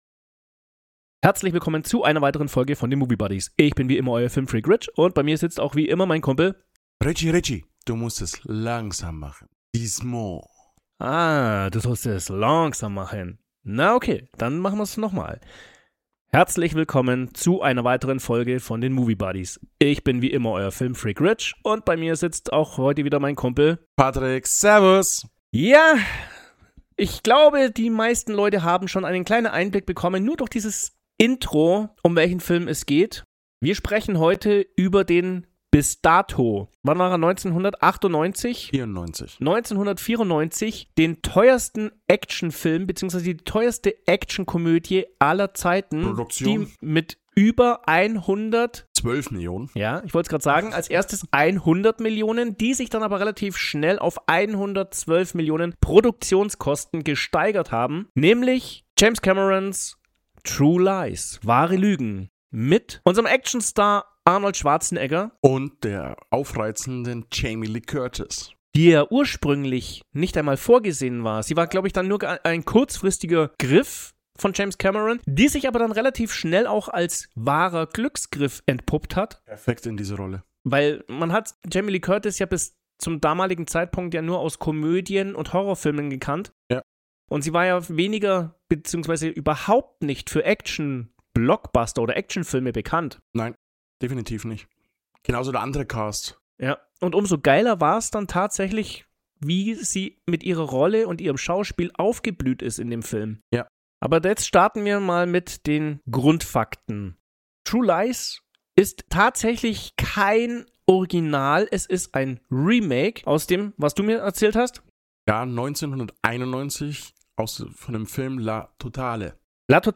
Erlebt eine witzige und informative Unterhaltung über einen phänomenalen Meilenstein des Actionkinos, der dieses Genre für alle Zeiten revolutionierte.